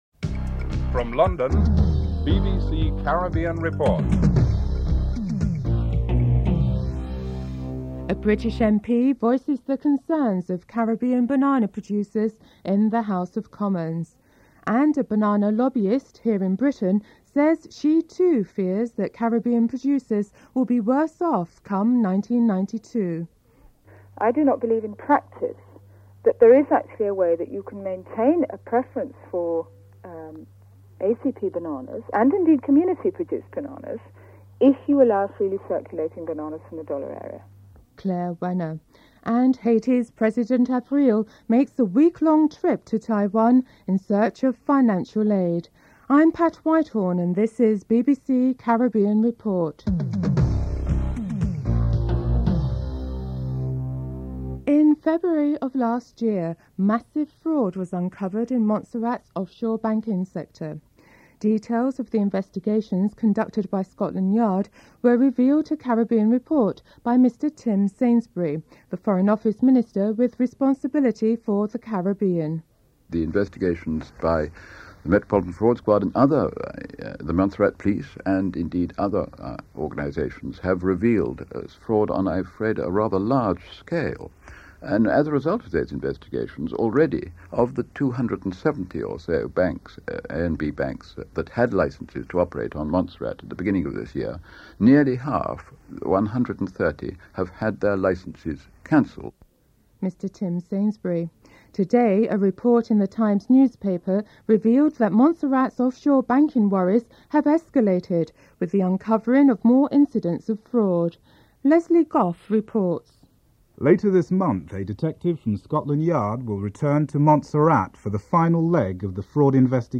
Secondly, a report addresses Caribbean concerns over the future of banana exports with the inception of the Single European Market. During a commodities debate in the British House of Commons, Labour Minister, George Foulkes questioned Lynda Chalker on the concerns of the WI banana producers.
1. Headlines (00:00 - 00:52)